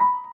piano_last08.ogg